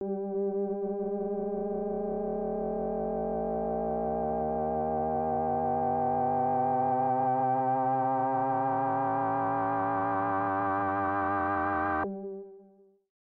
标签： MIDI-速度-32 FSharp4 MIDI音符-67 赤-AX80 合成器 单票据 多重采样
声道立体声